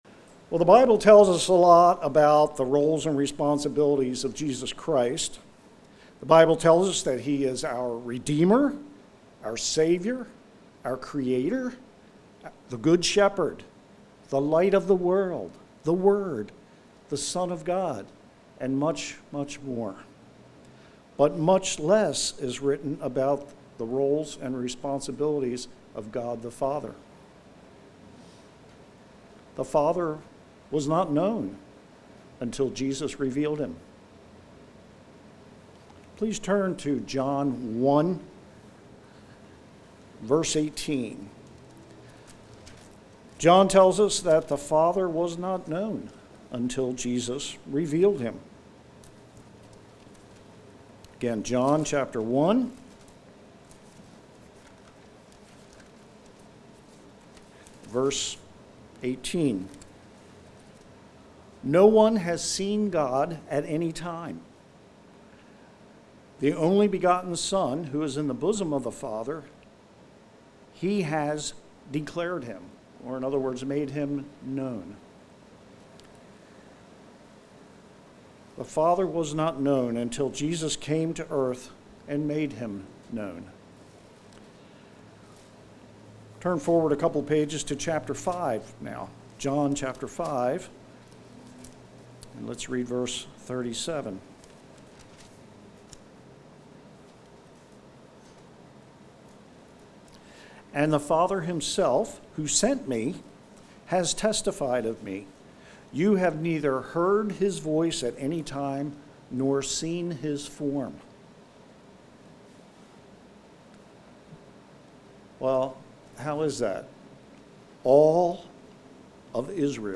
The primary role of God the Father is to be our Father. In this sermon, we will review God’s role as our Father.